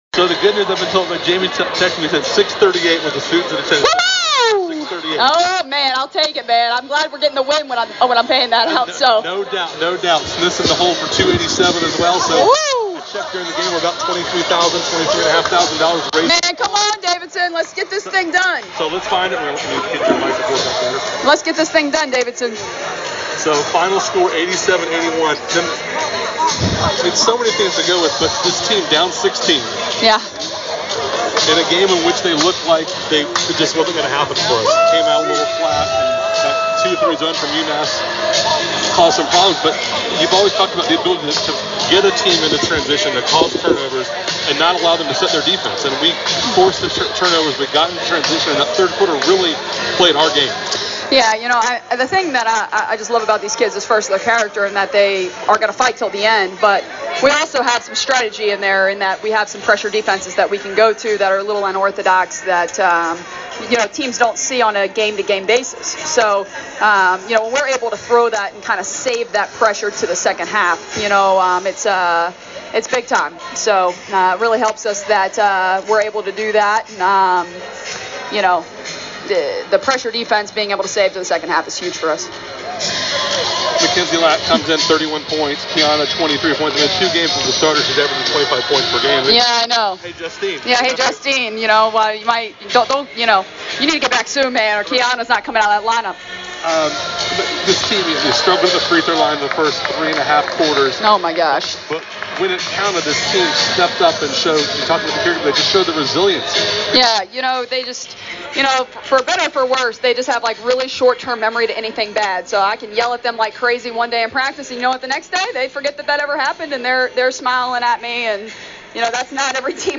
Postgame Comments
Post Game UMASS.mp3